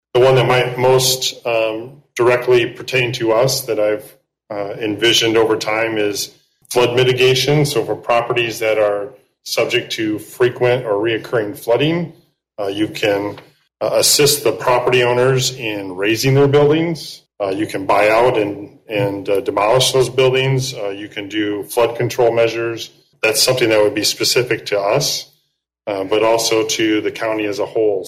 Coldwater City Manager Keith Baker said during last week’s City Council meeting the Plan defines a process for identifying, quantifying, and reducing risk in Branch County.